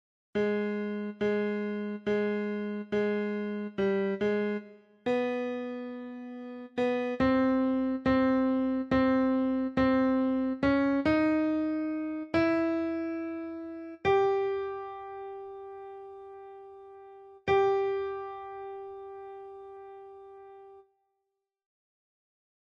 Key written in: E Major
Type: Barbershop
Each recording below is single part only.